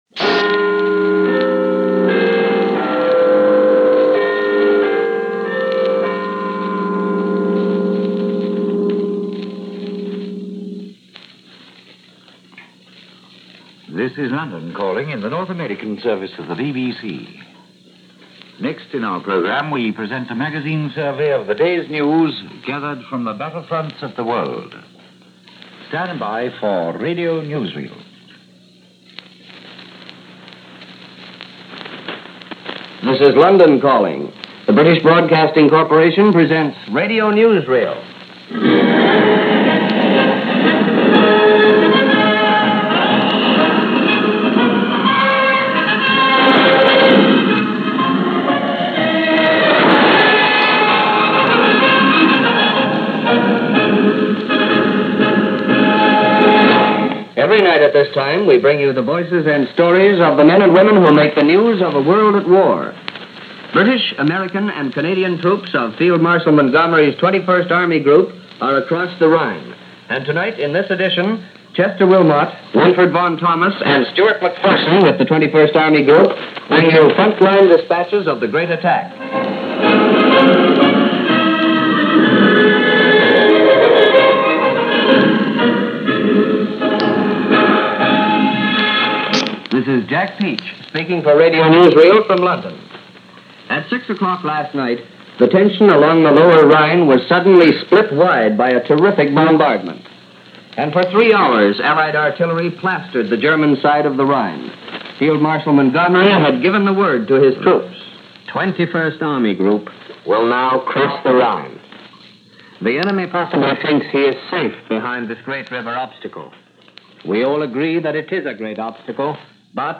March 25, 1945 – News reports for this day covered the continued Allied crossing of the Rhine River.